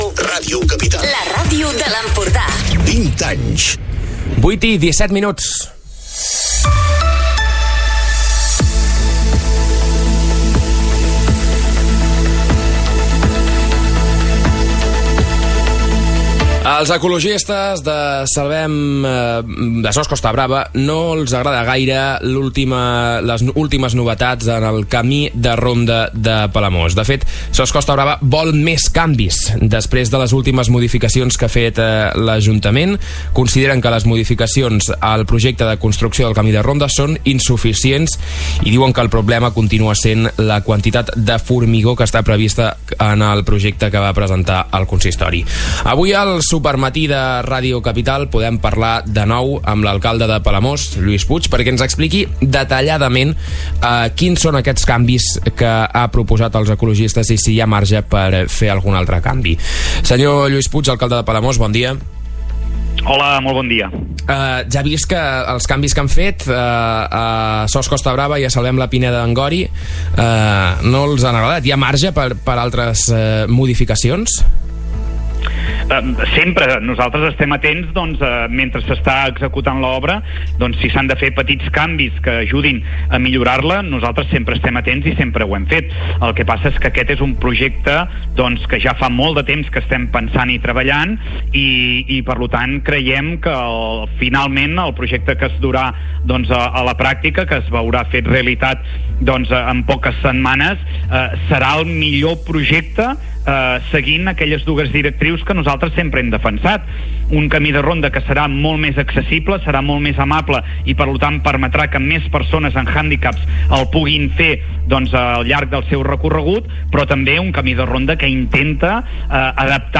lluis-puig-entrevista.mp3